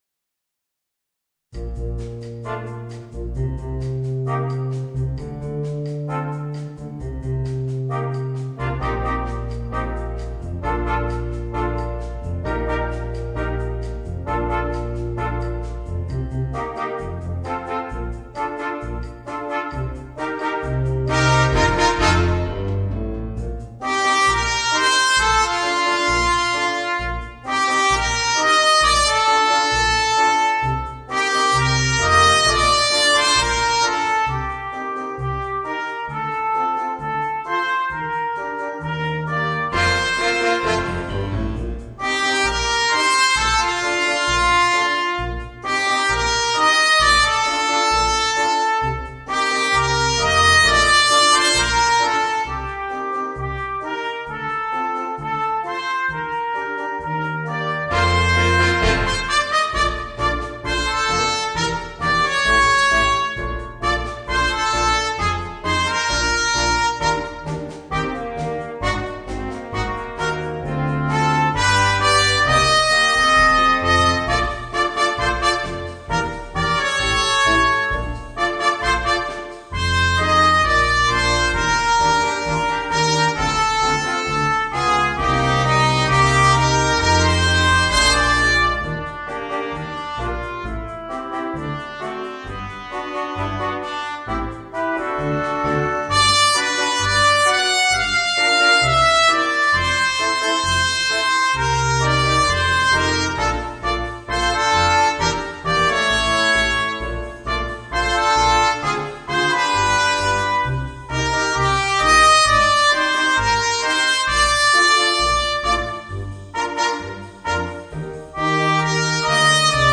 Voicing: 2 Trumpets, Trombone and Tuba